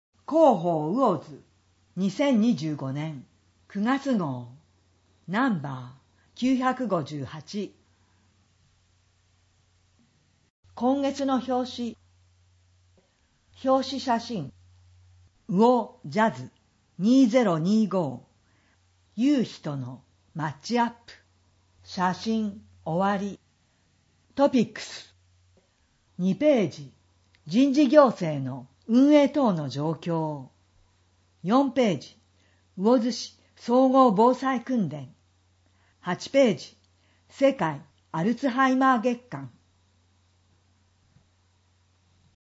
声の広報
魚津市では、音訳サークルうぐいすの会にご協力いただき、視覚障害の方を対象に「広報うおづ」の音訳CDを無料で発送しています。